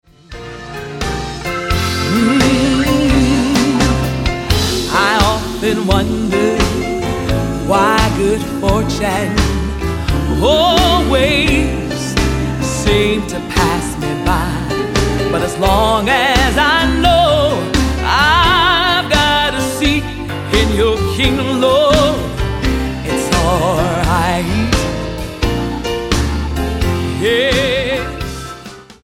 STYLE: Gospel
with a band re-creating a timeless, bluesy accompaniment